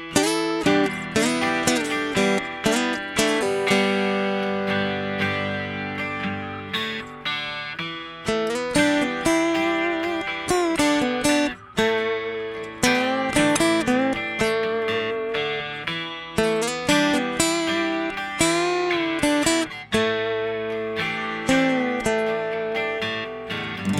no Backing Vocals Rock 4:57 Buy £1.50